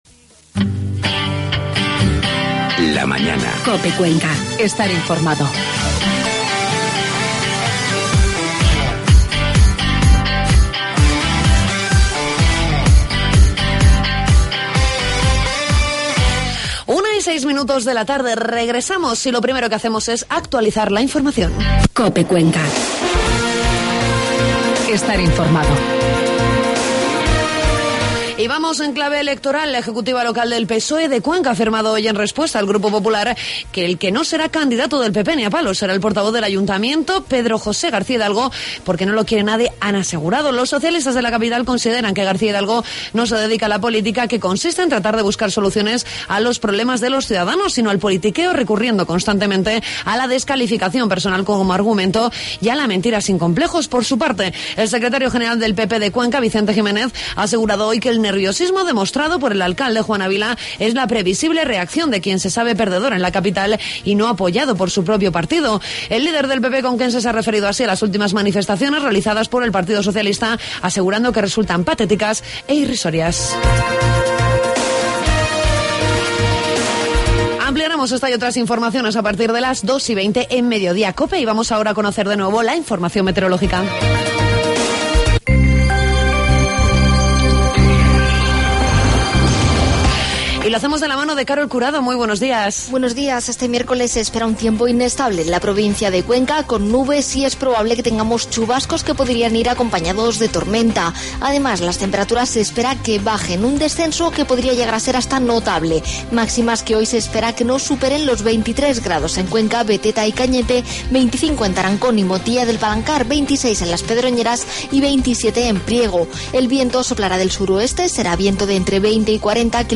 Entrevistamos a miembros de ASPADEC para conocer las IV Jornadas de puertas abiertas que comienzan el próximo lunes. Tambien conocemos la Semana Europea de la Movilidad a la que se une el centro Europa Direct de Cuenca.